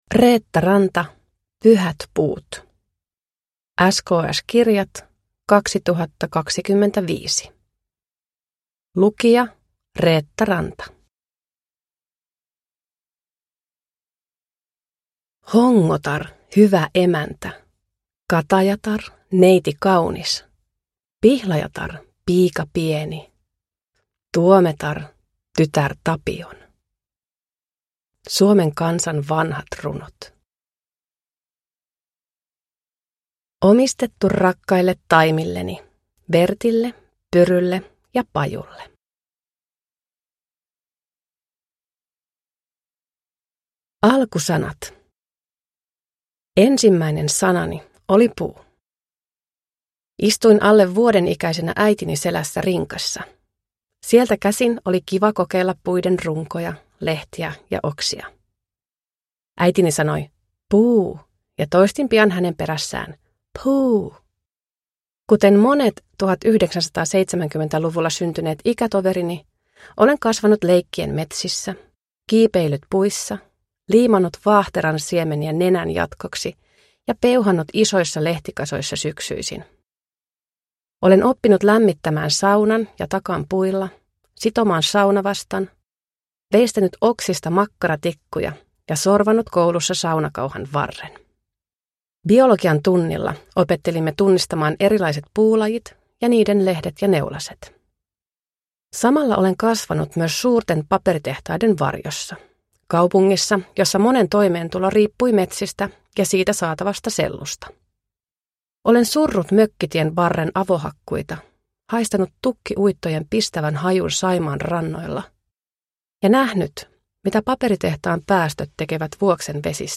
Pyhät puut – Ljudbok